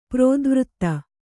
♪ prōdvřtta